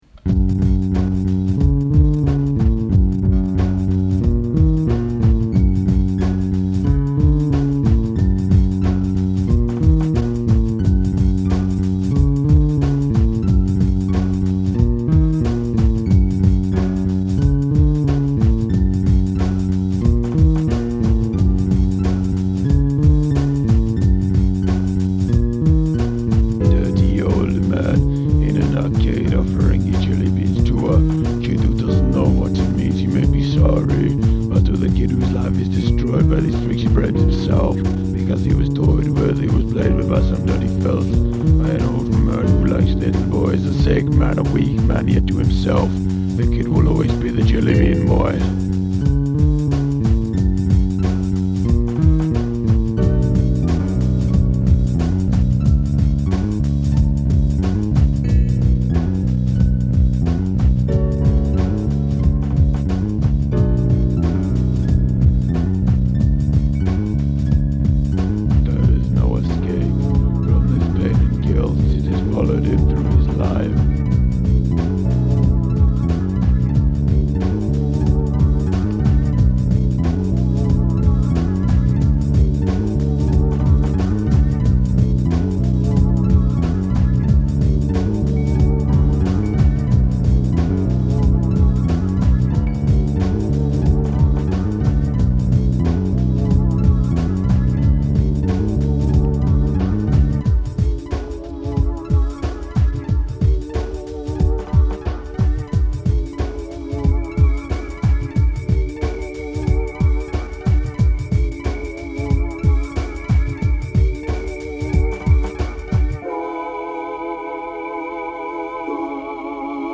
Early MIDI and sample experiments.